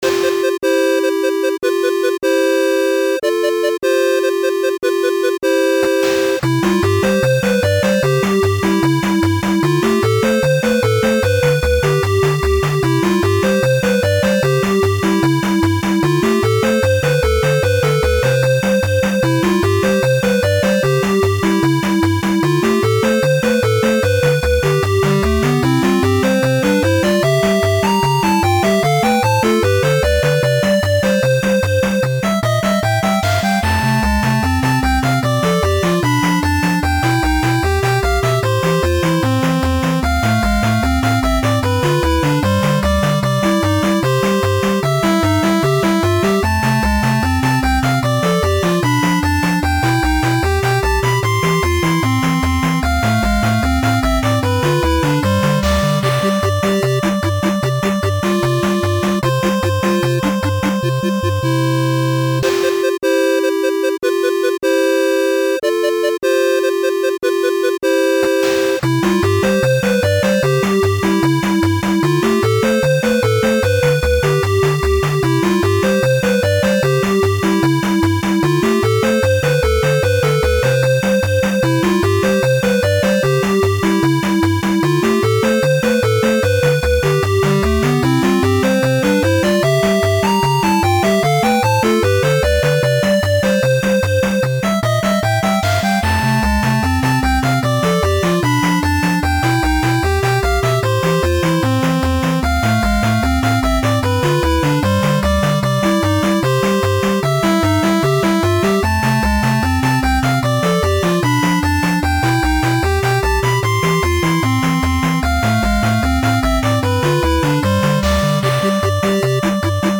たのしいBGM
街など平和で愉快な曲があります。